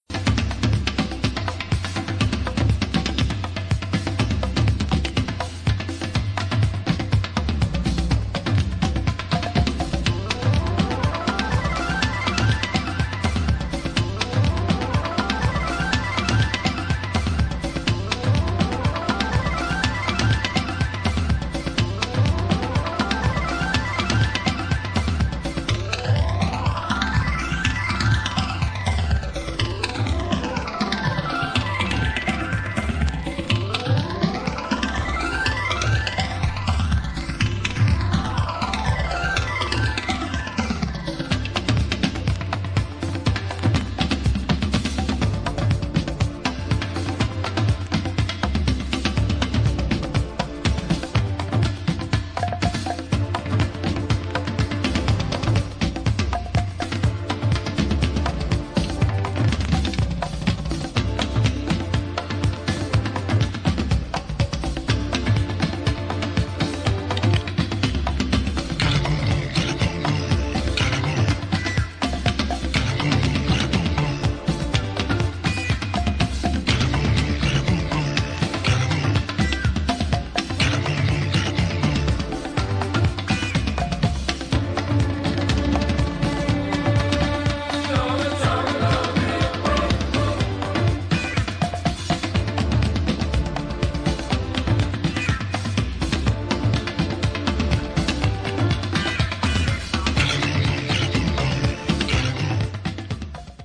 ディスコ・リエディット